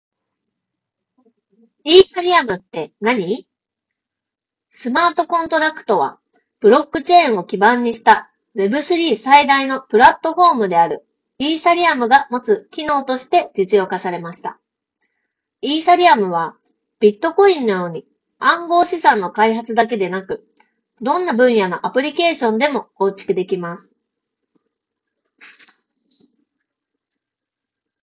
ノイキャン効果は高く、周囲のノイズを効果的に取り除き、装着者の声のみをクリアに拾い上げることができていた。
さすがに専用のハイエンドマイクと比べると録音品質は劣るものの、普通に通話するには十分に優れた性能となっている。
▼SOUNDPEATS CCの内蔵マイクで拾った音声単体
録音音声を聴くと、周囲の環境ノイズ(空調音や屋外の音)を効果的に除去し、発言内容を明瞭に拾い上げることができていることが分かる。